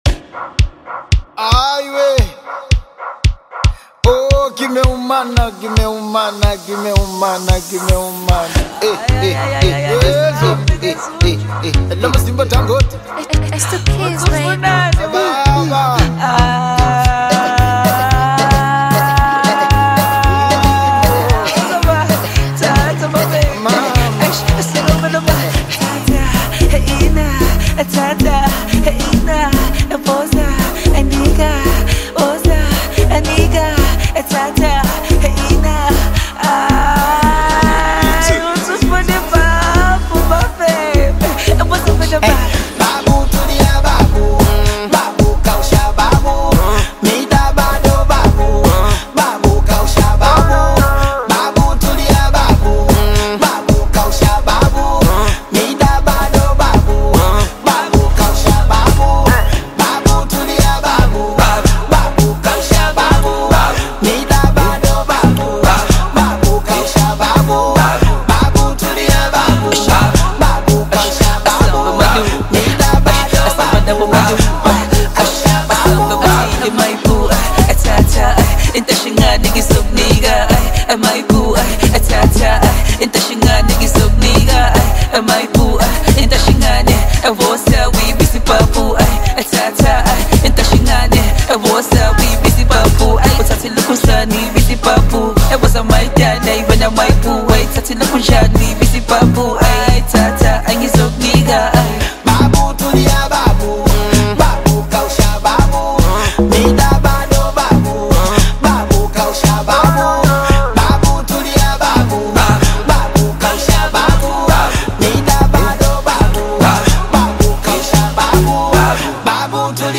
fresh and energetic Amapiano anthem
high-energy Amapiano track
signature Bongo Flava vocals
smooth South African flow